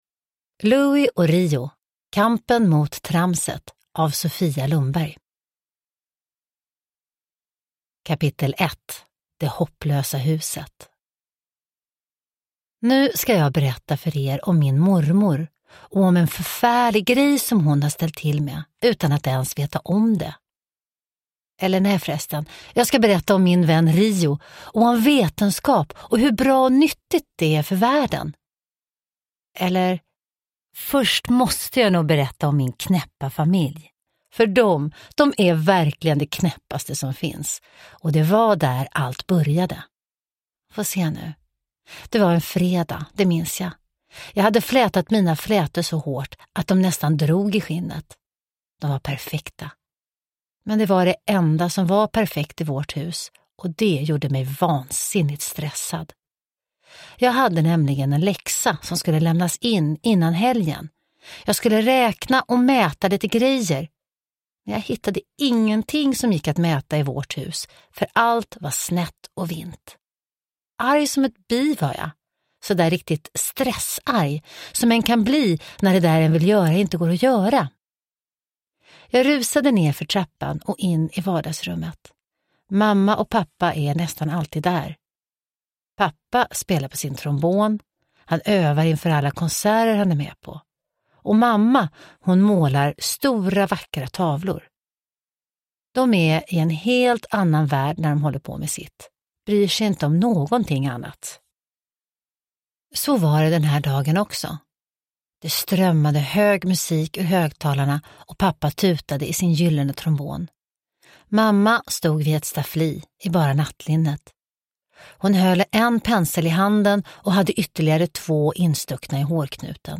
Kampen mot tramset – Ljudbok – Laddas ner
Uppläsare: Johanna Westman